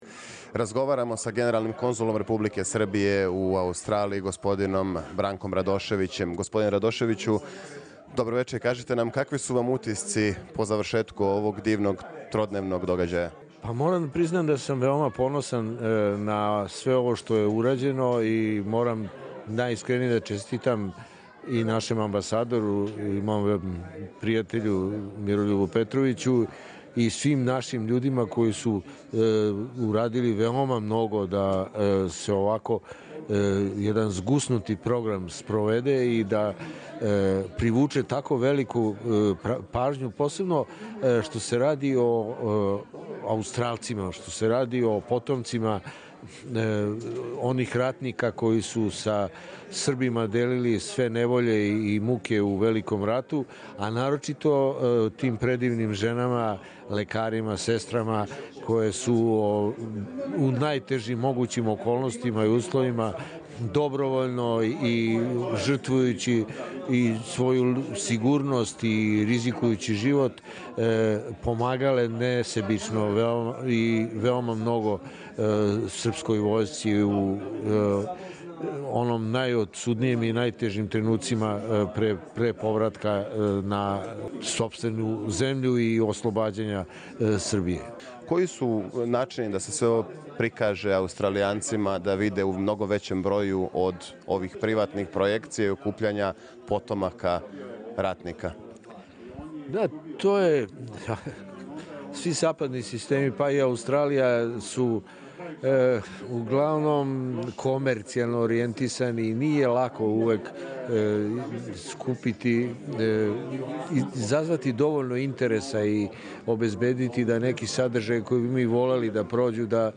Генерални конзул Србије у Сиднеју ЊЕ г. Бранко Радошевић о утисцима са свечане комеморације у Ратном меморијалном центру у Камбери и пропратним манифестцијама које су одржаване од 14 до 16. септембра.